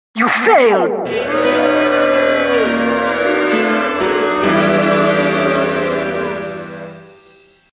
you failed team fortress 2 Meme Sound Effect
you failed team fortress 2.mp3